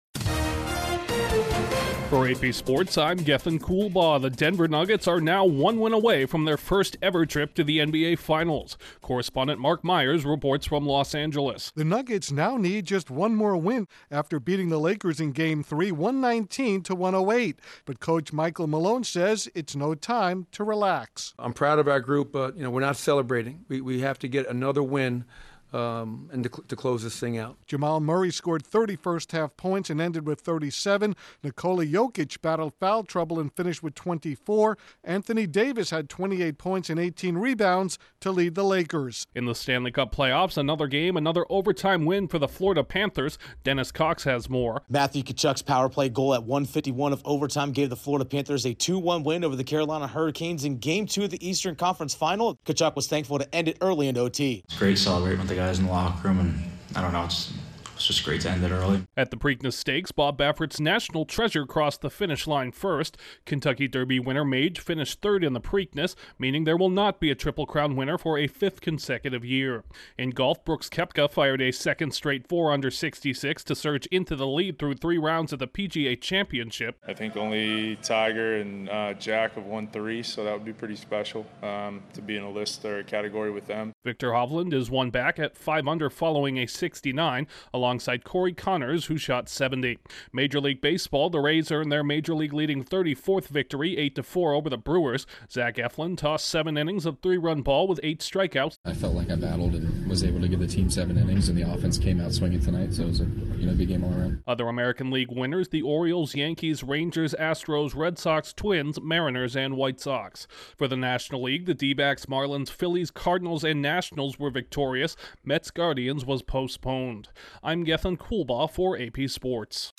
AP News Summary at 1:00 a.m. EDT